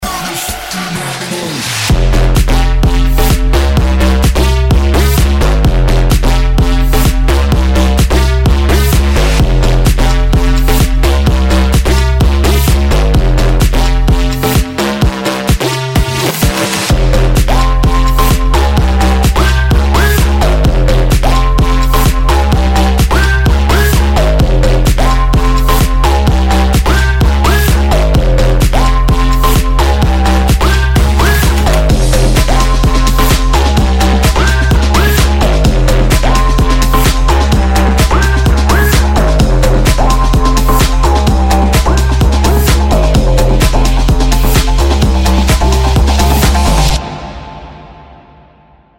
• Качество: 192, Stereo
Очень крутой Электро-ремикс самого известного хита